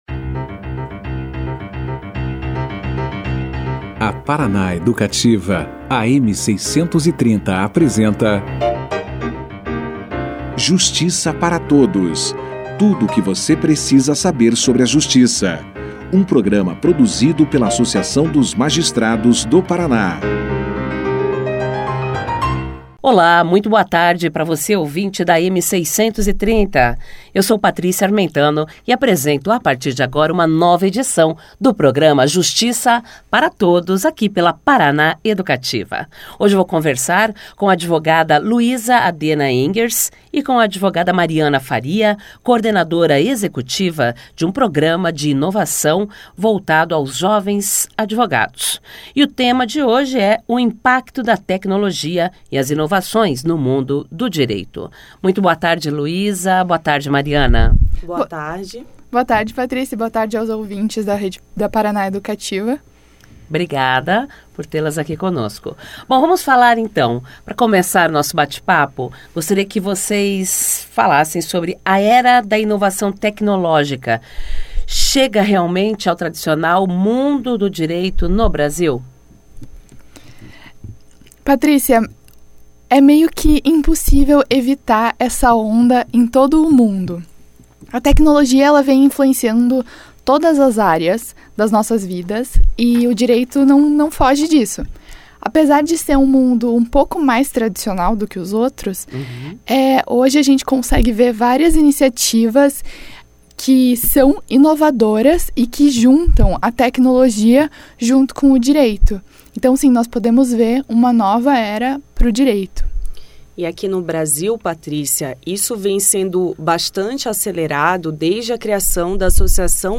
De que forma à tecnologia chegou a área do direito brasileiro, como ela contribui para o trabalho desenvolvido pelos profissionais da área e as principais inovações tecnológicas presentes no universo jurídico, foram questões esclarecidas no início da entrevista. Durante a conversa, as convidadas fizeram uma análise do atual cenário universitário com relação ao assunto e destacaram os métodos alternativos de resolução de conflito e suas especificidades. Confira aqui a entrevista na íntegra.